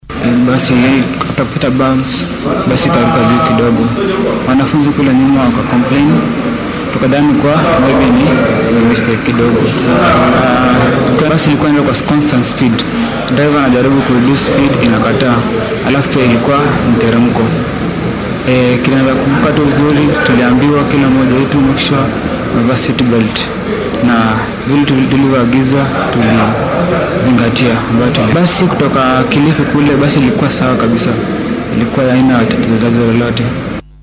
Mid ka mid ah ardayda shilka ku dhaawacmay ayaa ka warramay sida ay wax u dhaceen.